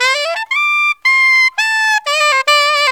SAX JN LIC04.wav